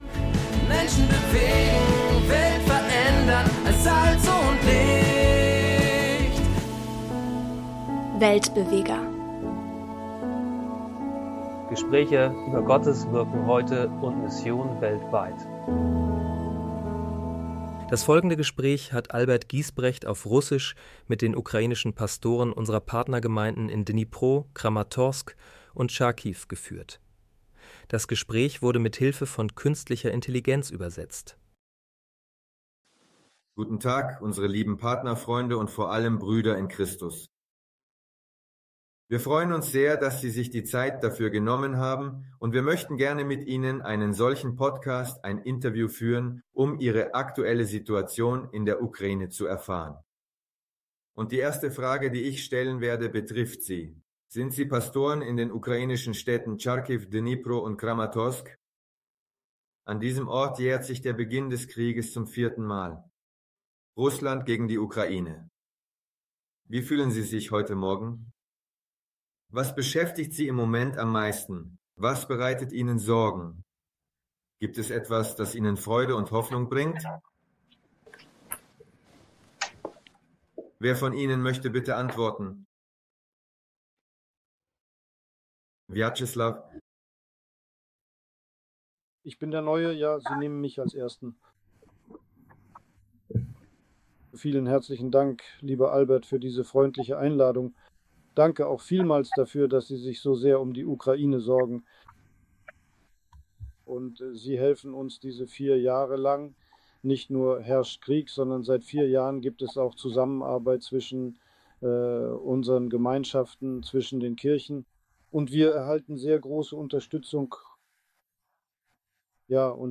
mit drei Pastoren aus der Ukraine über ihren Alltag, ihren Glauben und ihren Dienst mitten im Krieg. Vier Jahre nach Beginn der russischen Invasion berichten sie ehrlich aus ihren Städten in der Ukraine – von Kälte, Stromausfällen, Bombardierungen, aber auch von Hoffnung, Gemeinschaft und Gottes Wirken.